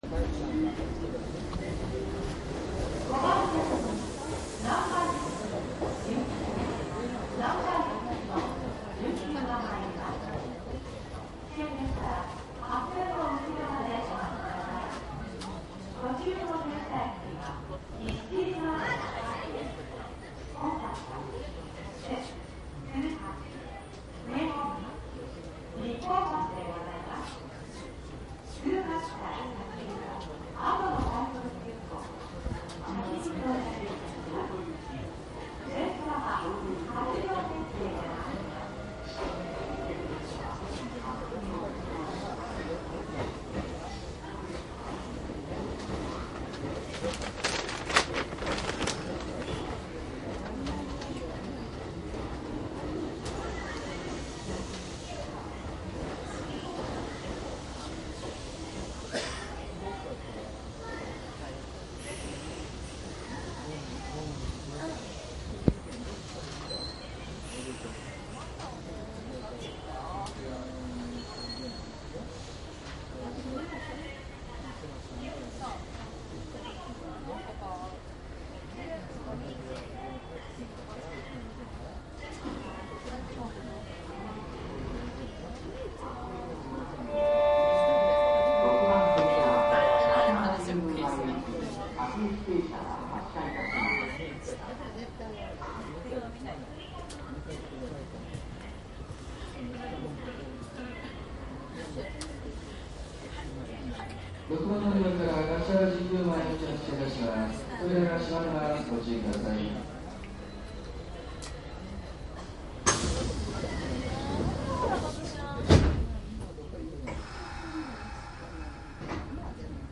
近鉄8810系 走行音CD
■【普通】西大寺→橿原神宮前 8811
マスター音源はデジタル44.1kHz16ビット（マイクＥＣＭ959）で、これを編集ソフトでＣＤに焼いたものです